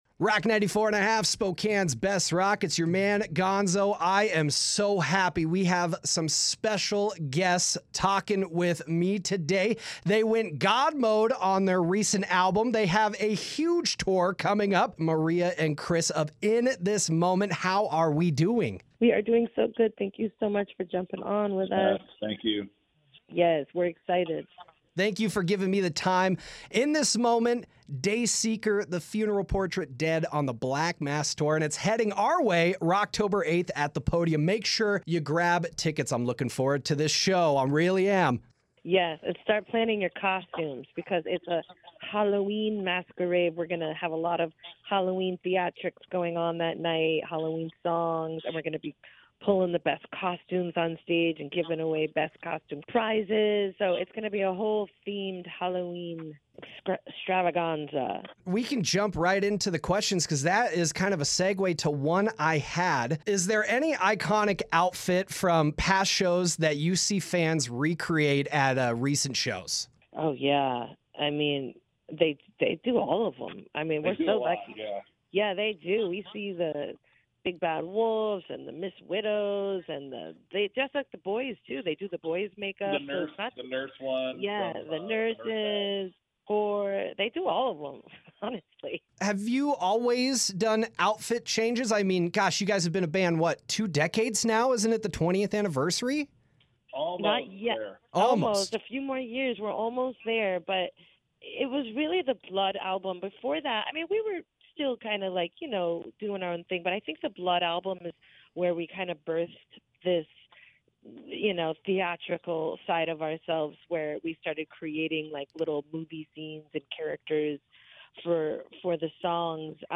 In This Moment Interview with Chris Howorth and Maria Brink
interview-with-maria-brink-and-chris-howorth.mp3